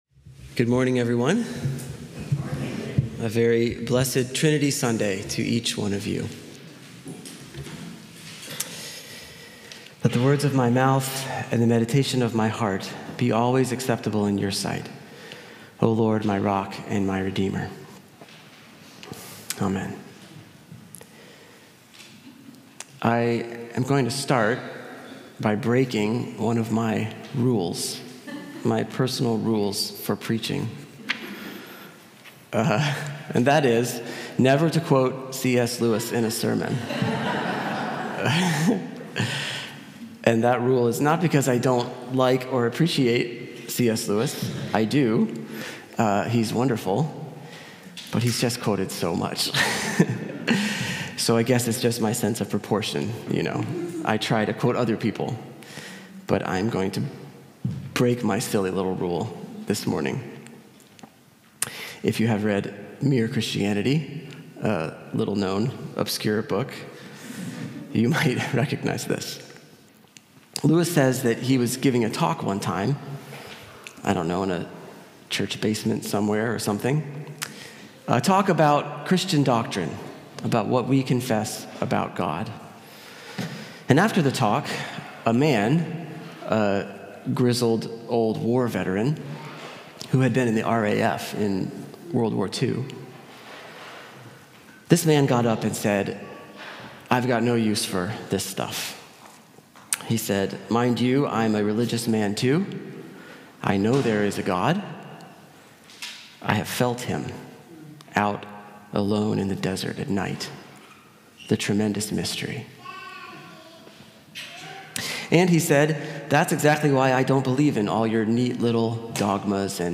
COR Sermon – June 15, 2025 – Church of the Redeemer